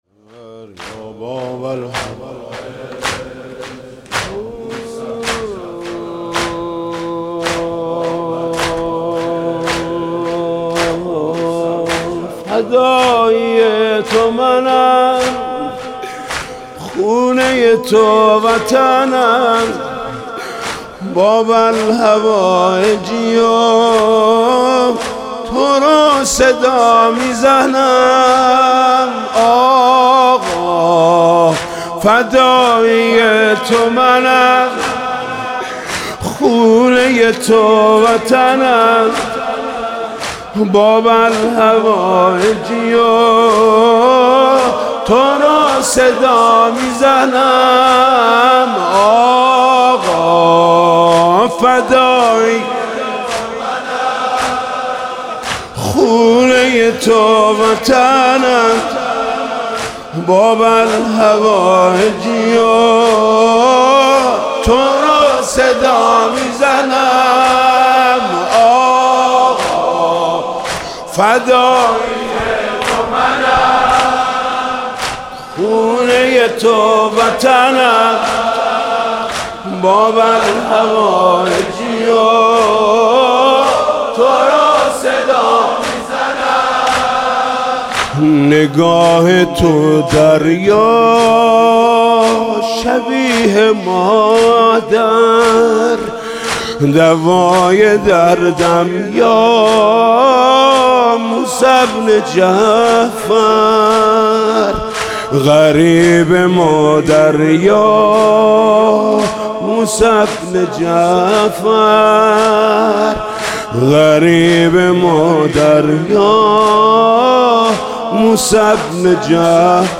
زمینه: فدایی تو منم خونه ی تو وطنم